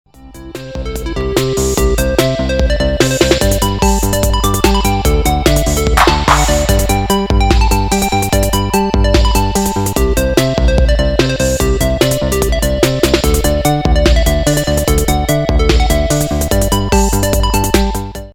Minimal synth Unique 45t retour à l'accueil